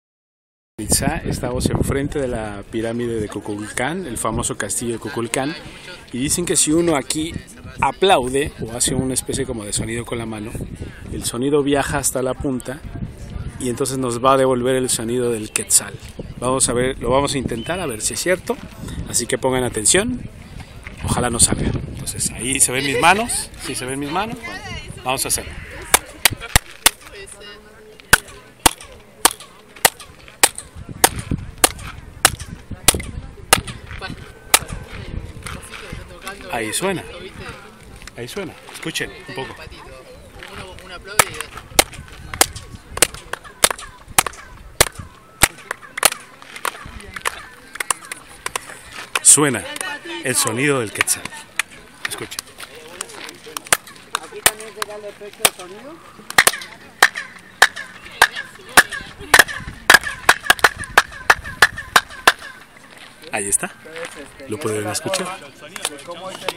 Si se aplaude desde la base de la pirámide, este sonido sube por sus escaleras, rebotando finalmente en su templete superior. El resultado es un eco distorsionado denominado como el canto del Quetzal, ya que el sonido es muy parecido al canto de este pájaro sagrado de los mayas. Pueden escuchar el sonido del Quetzal en el siguiente audio: